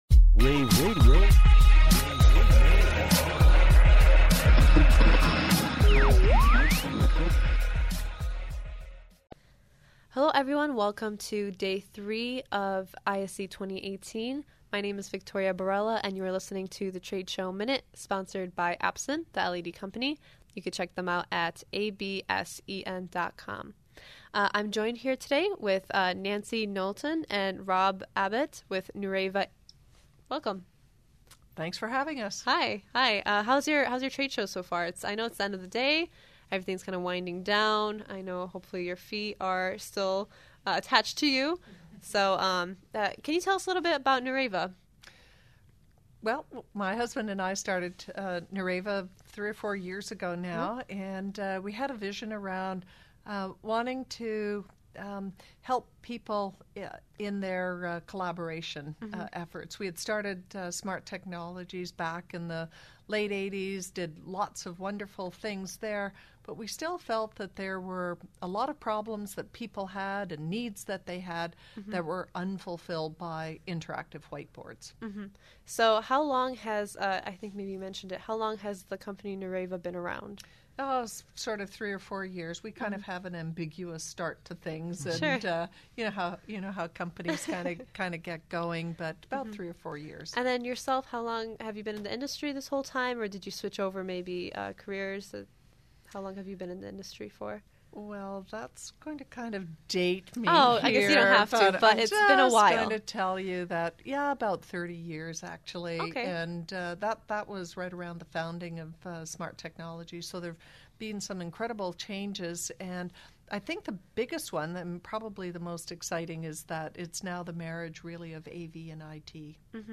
February 8, 2018 - ISE, ISE Radio, Radio, The Trade Show Minute,
Nureva closes out Day 3 of ISE 2018.